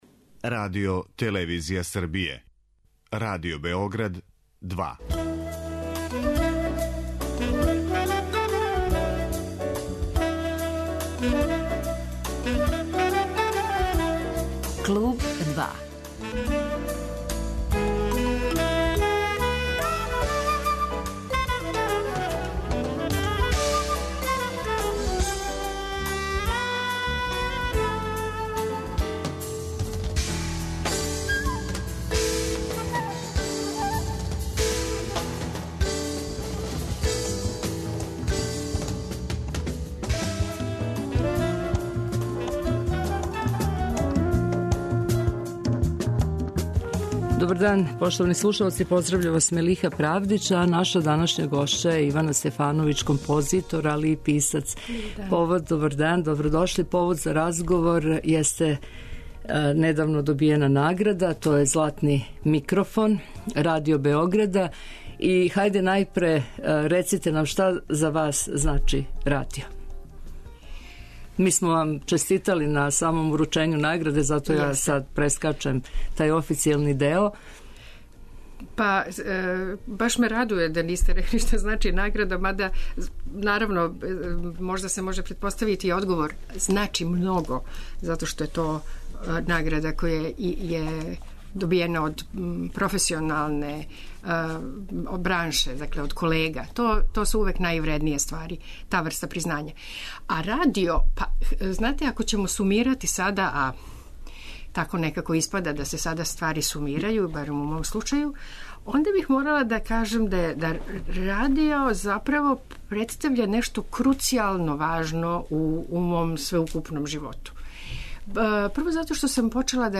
Гошћа 'Клуба 2' je композиторка Ивана Стефановић.